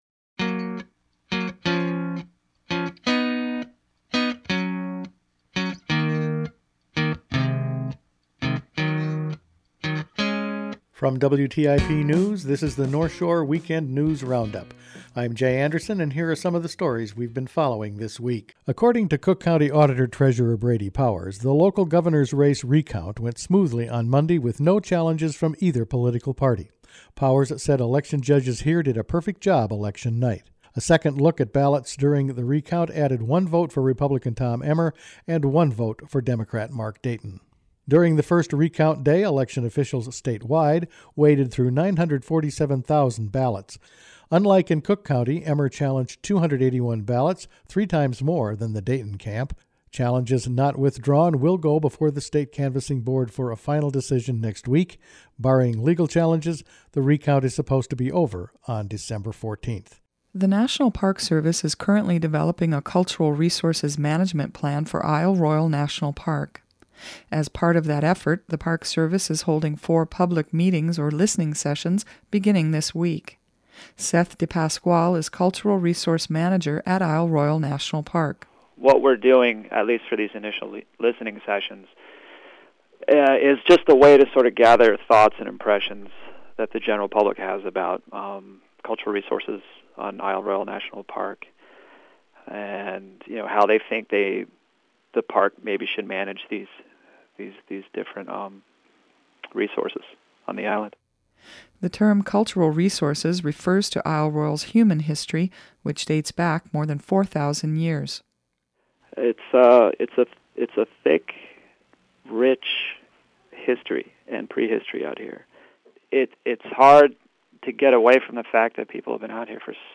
Weekend News Roundup for Dec. 4